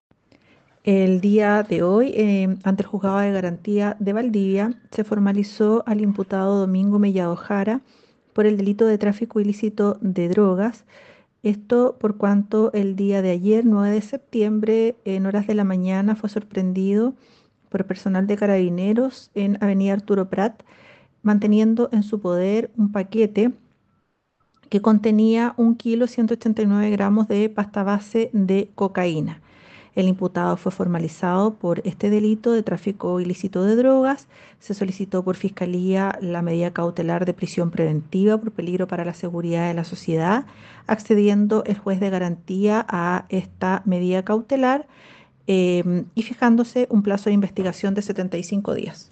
FISCAL-ALEJANDRA-SOTO.m4a